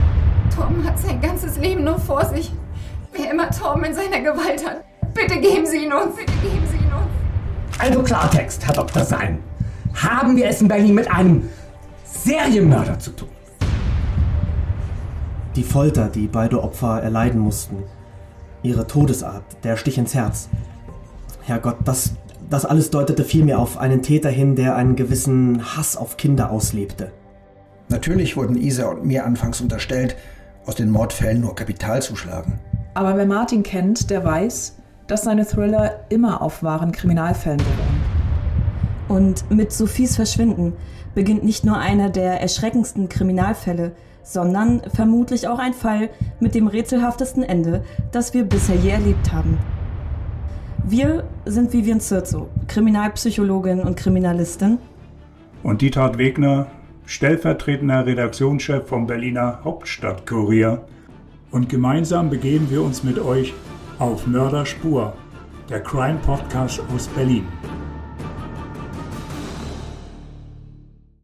(als Kriminalpsychologin)
(als Radiosprecherin)